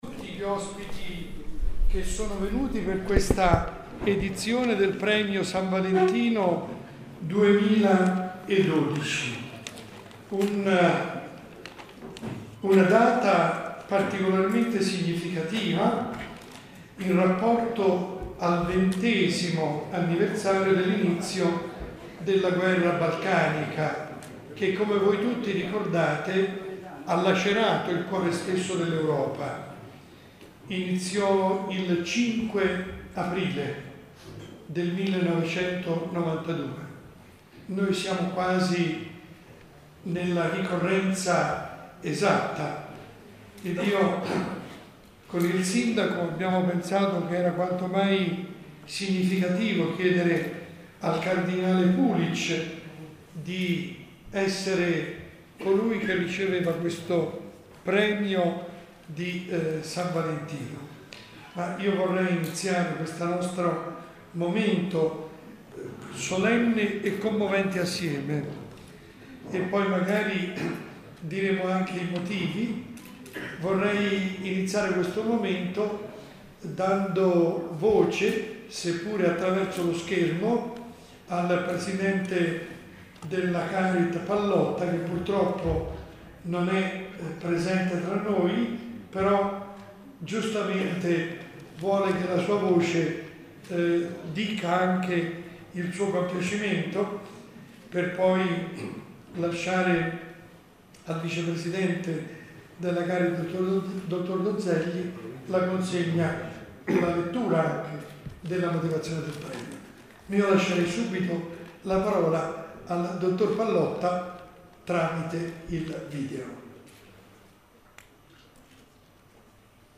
La registrazione integrale dell’intervento